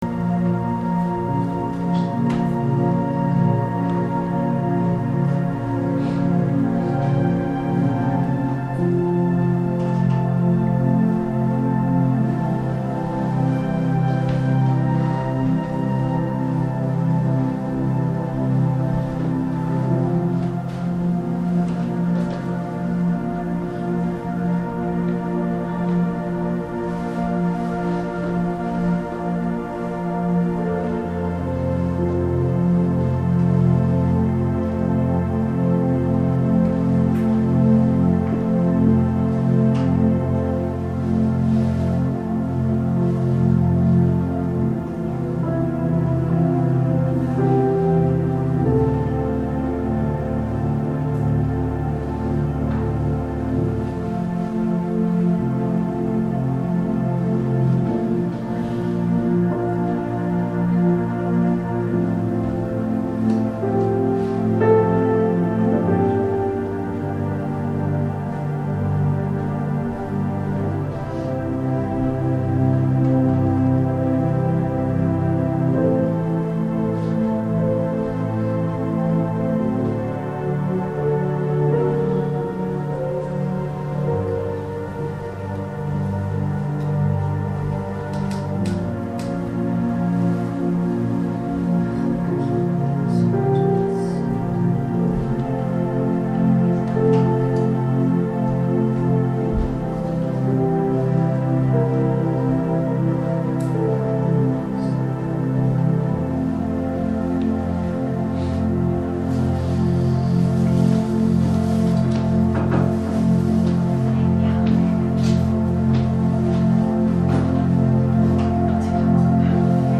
主日恩膏聚会（2015-12-13）